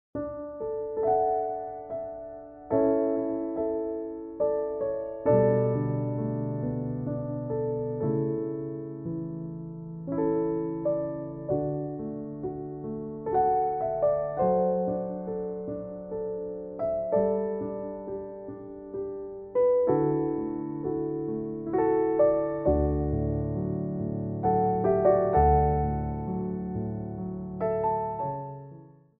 Ports de Bras 1
3/4 (8x8)